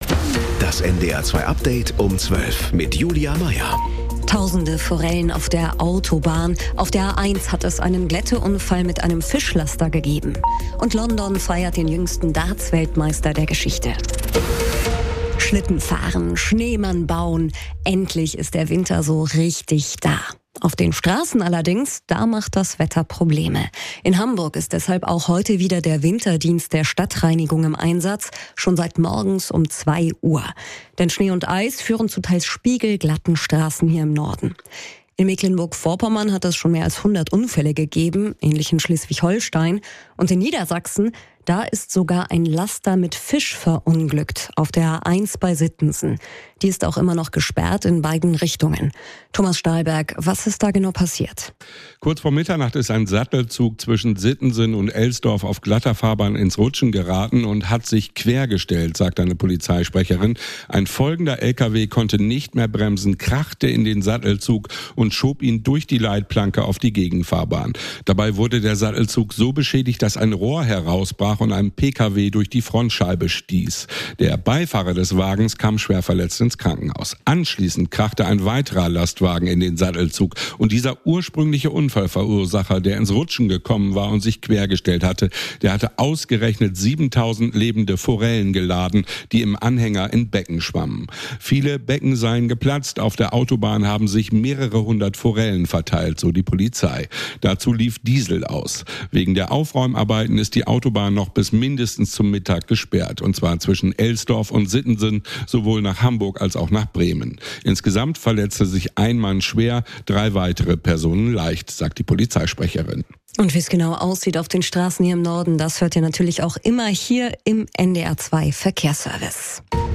Mit unseren Korrespondent*innen und Reporter*innen, im Norden, in Deutschland und in der Welt.
… continue reading 227 episodi # NDR 2 # Tägliche Nachrichten # Nachrichten # NDR # News # Kurier Um 12 # Update Um 12